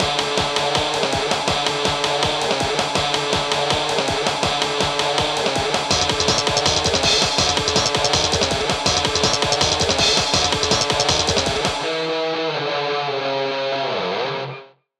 いつか長めのフリーBGMでも出してみたいかっこいいギター曲を作ってみました！スラップベースがお気に入りです！
ループ：◎
BPM：160 キー：Dm ジャンル：あかるい 楽器：ギター